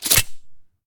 select-auto-shotgun-2.ogg